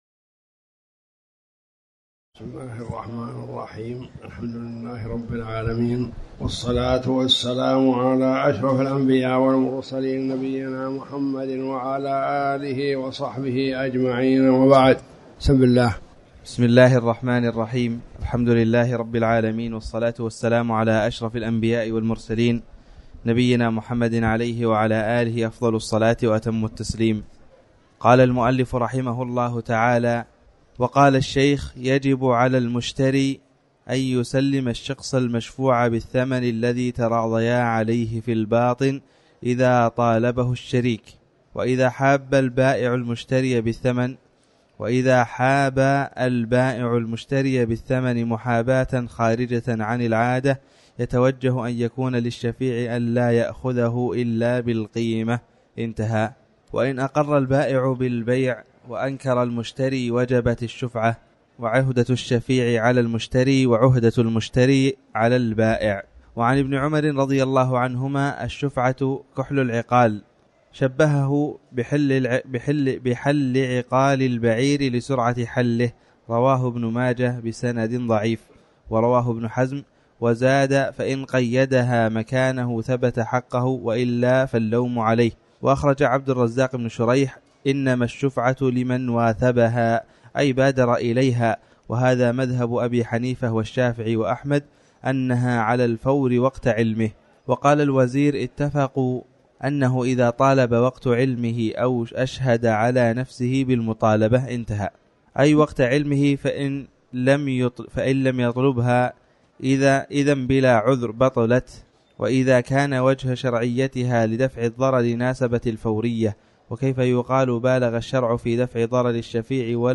تاريخ النشر ١٧ جمادى الآخرة ١٤٤٠ هـ المكان: المسجد الحرام الشيخ